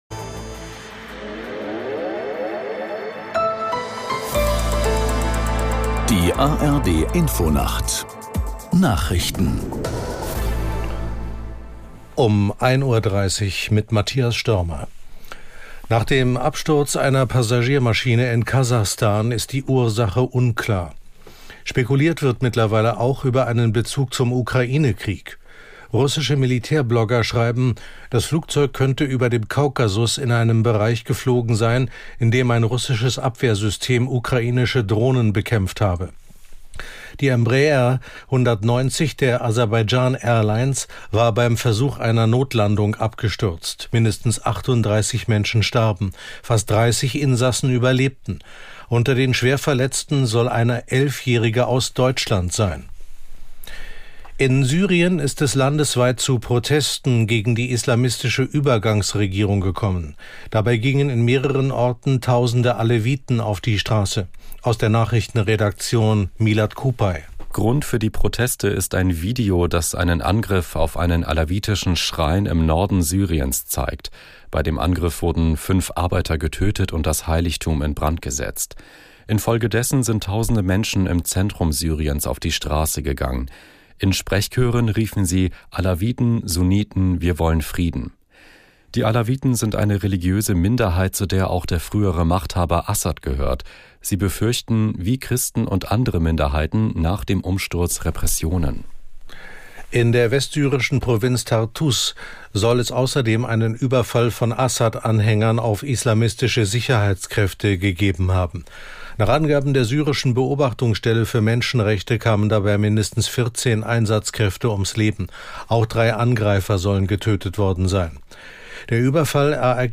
Nachrichten für den Norden.